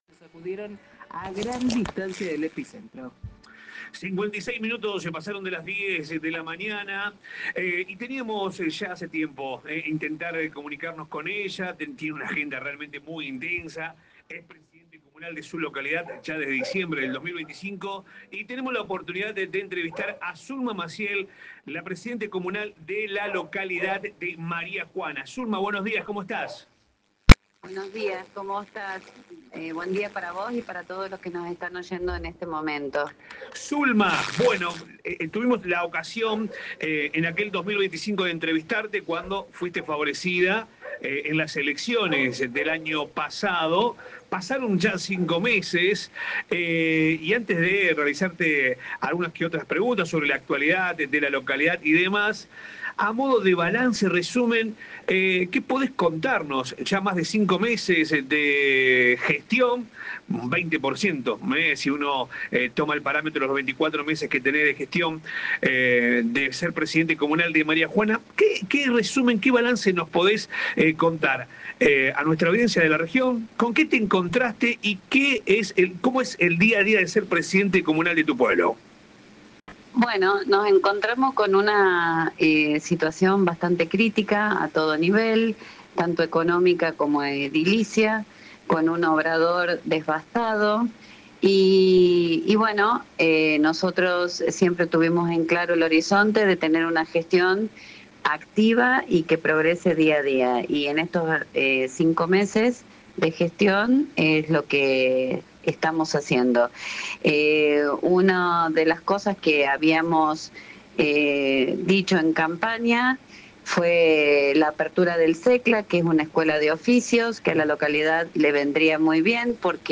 AUDIO COMPLETO DE LA NOTA A ZULMA MACIEL